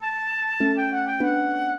flute-harp
minuet13-4.wav